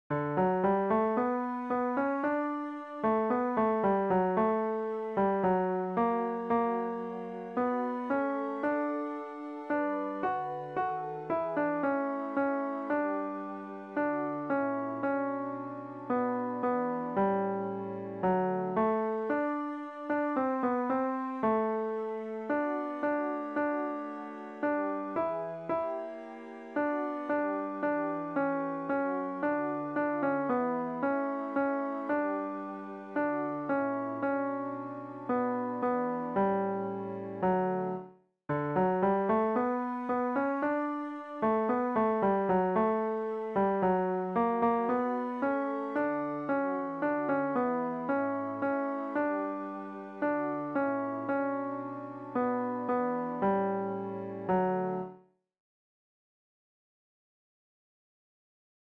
Chorproben MIDI-Files 510 midi files